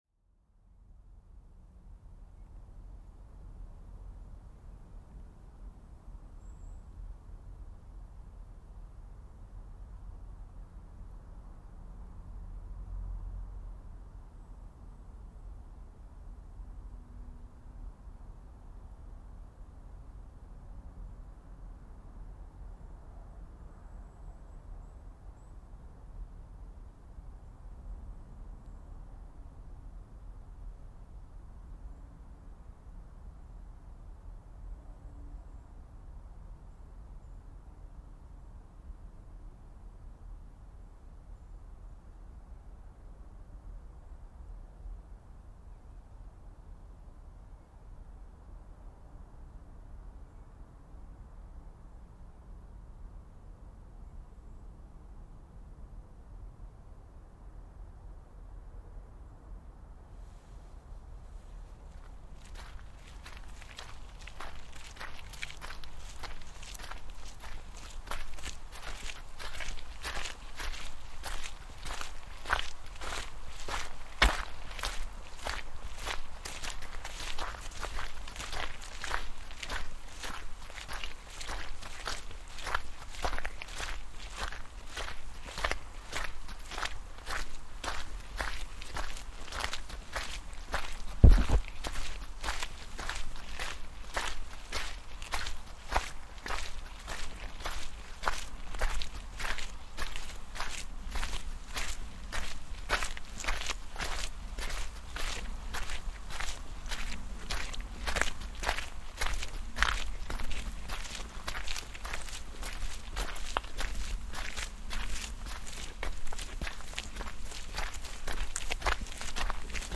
A walk in the Norman Forest (Field Recording)
Recorded in Normandy, late December.
muddy-walk.mp3